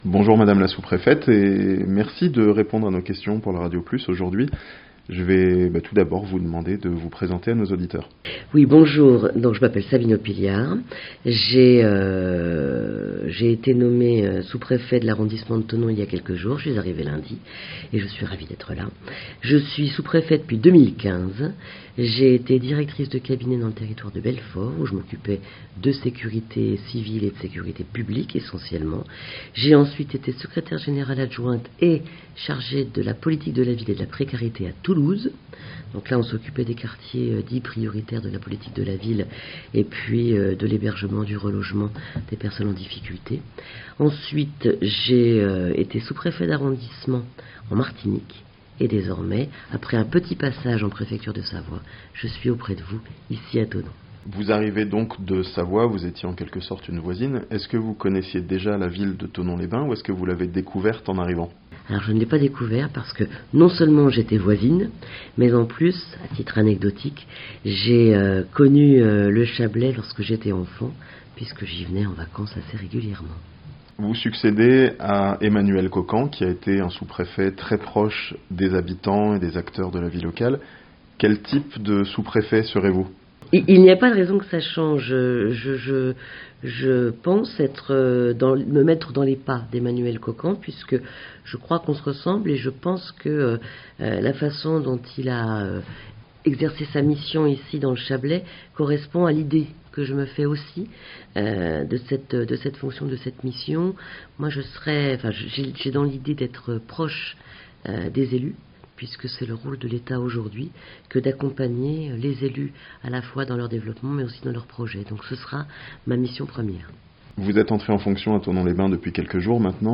Sabine Oppilliart, nouvelle sous-préfète de Thonon-Les-Bains (interview)